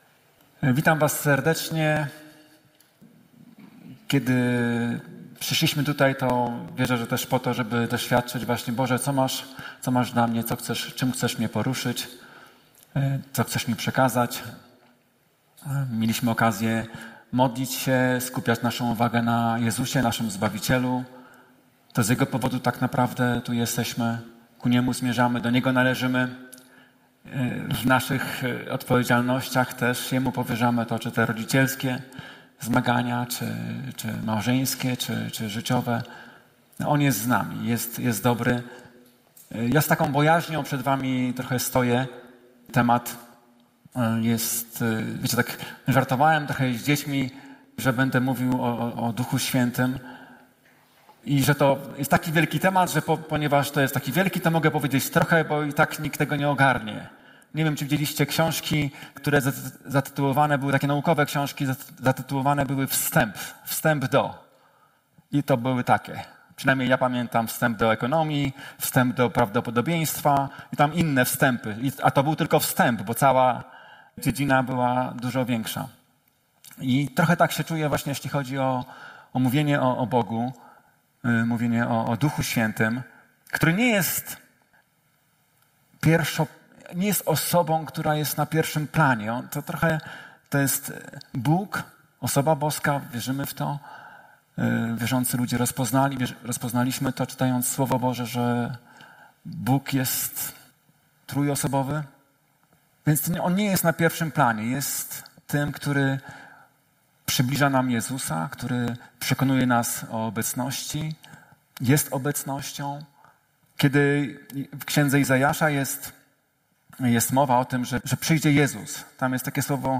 Transmisja nabożeństwa